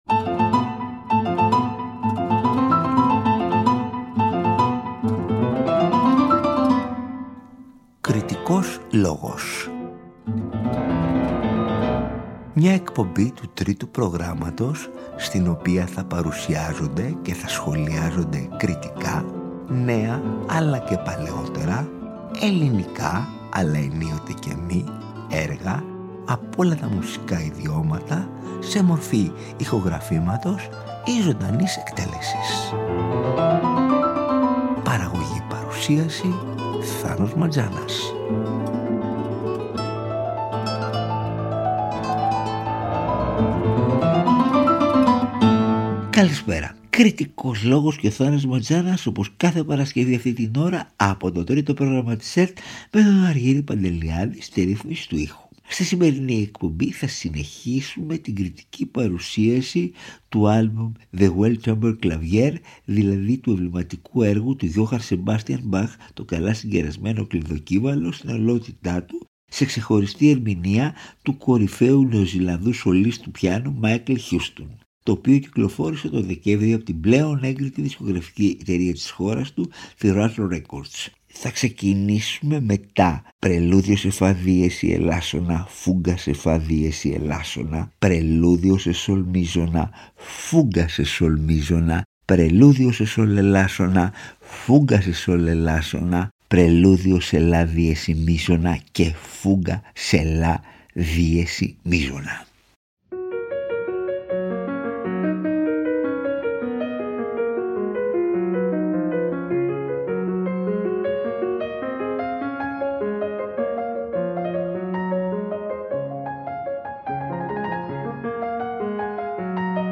Ακούστε την δεύτερη, που μεταδόθηκε την Παρασκευή 17 Ιανουαρίου 2025 από το Τρίτο Πρόγραμμα.